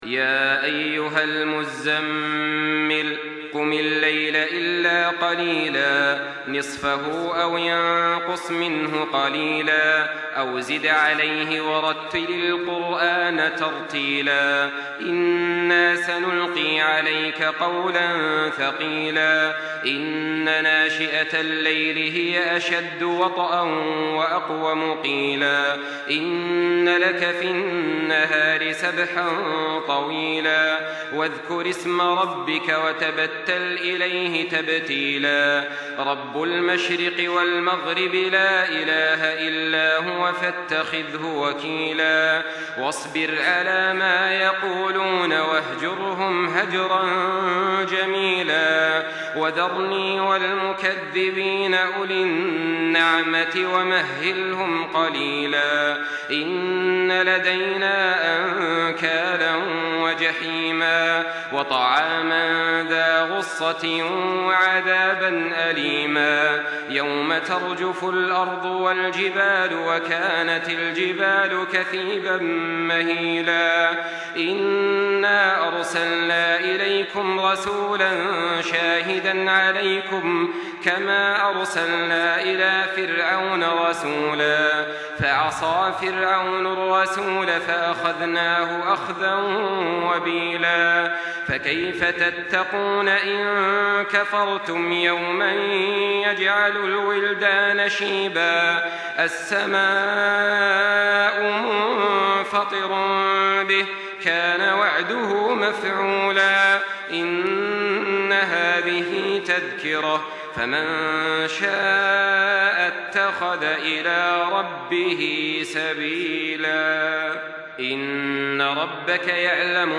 تحميل سورة المزمل بصوت تراويح الحرم المكي 1424
مرتل حفص عن عاصم